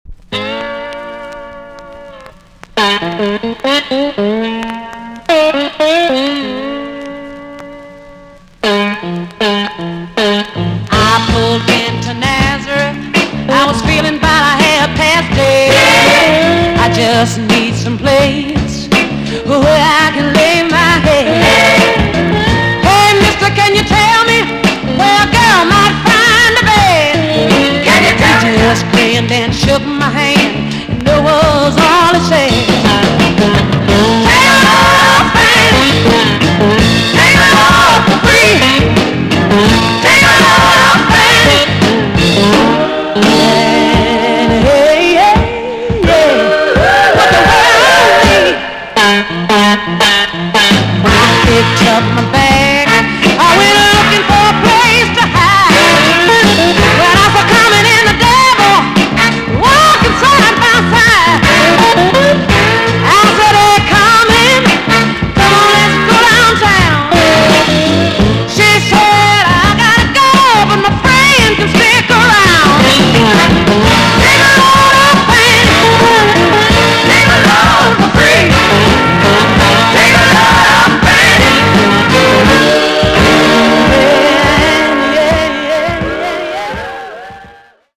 TOP >JAMAICAN SOUL & etc
VG+ 少し軽いチリノイズがあります。